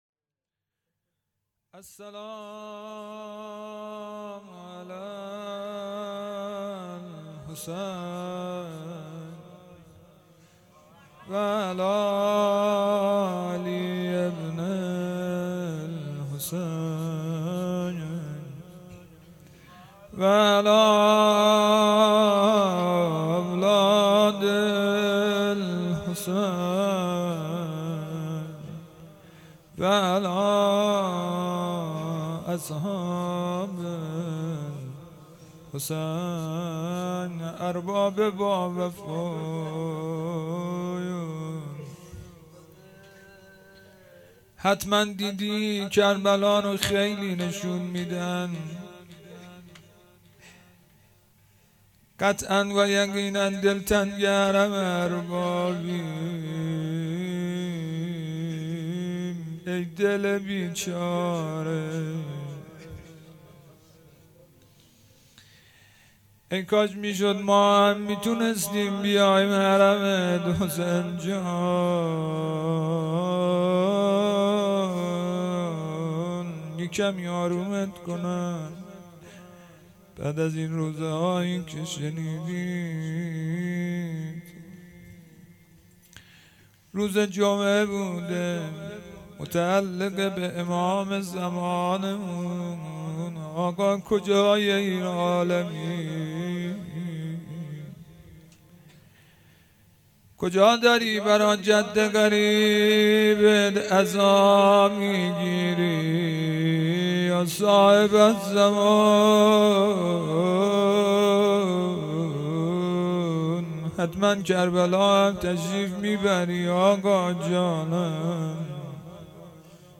هيأت یاس علقمه سلام الله علیها
شب هفتم محرم الحرام 1441